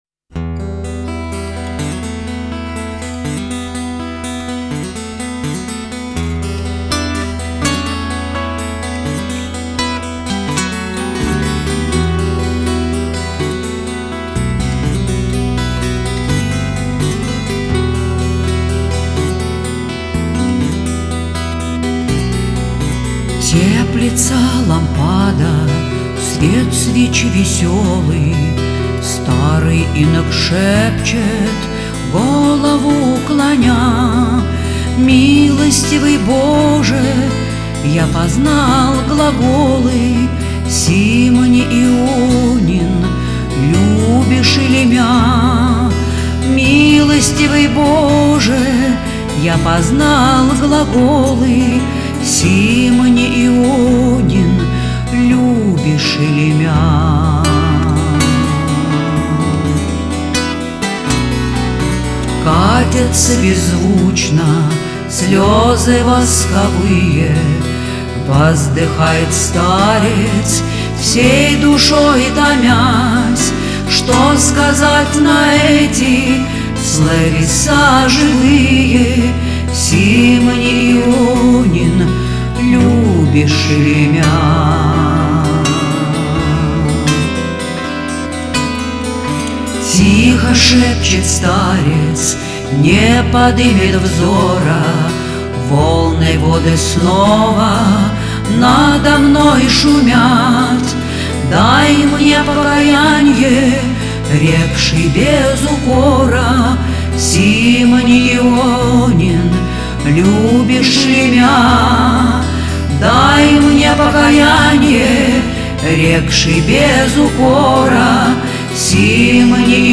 Православная музыка